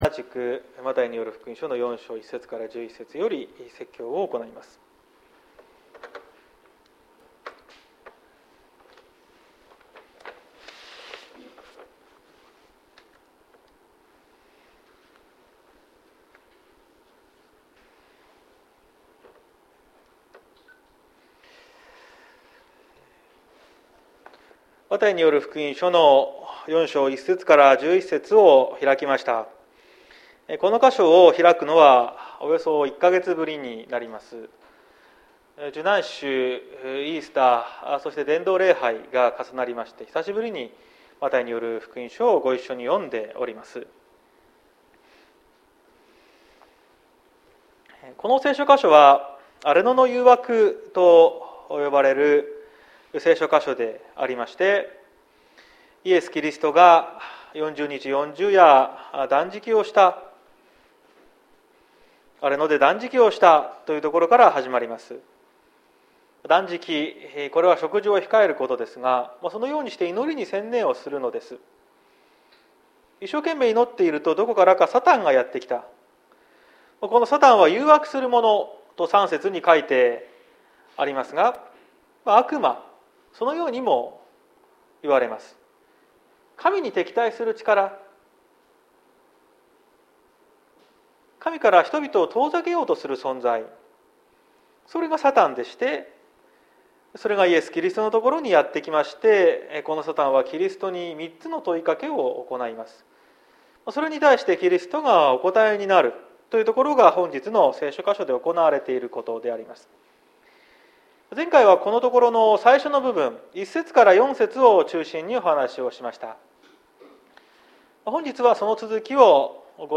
2022年05月01日朝の礼拝「誘惑（二）」綱島教会
綱島教会。説教アーカイブ。